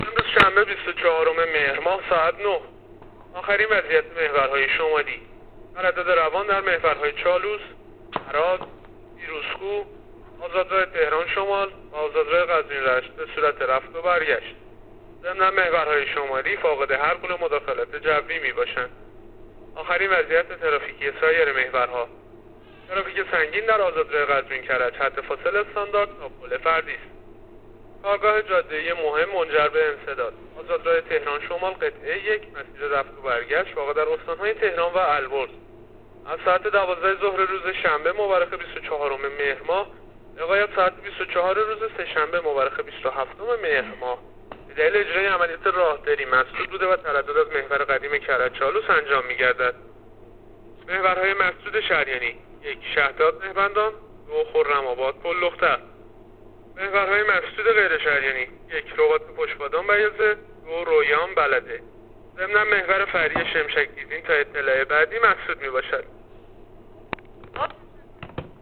گزارش رادیو اینترنتی از آخرین وضعیت ترافیکی جاده‌ها تا ساعت ۹ بیست‌وچهارم مهر؛